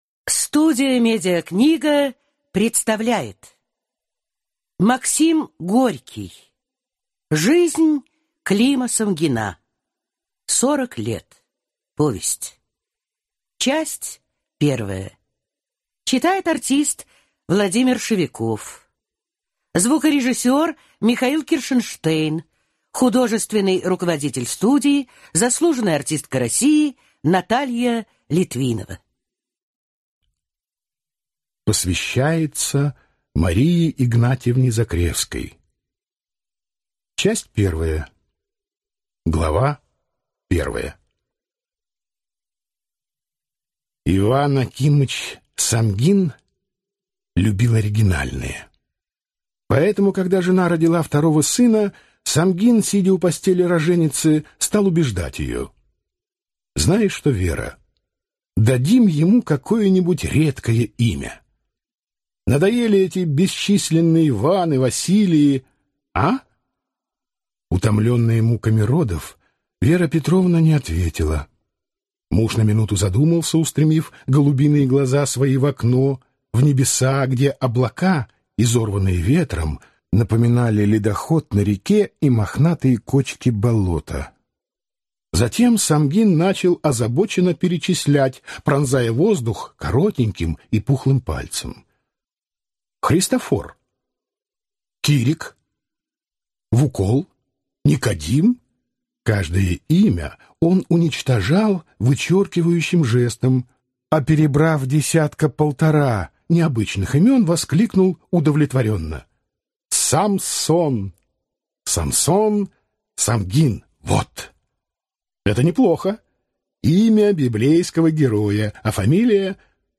Аудиокнига Жизнь Клима Самгина | Библиотека аудиокниг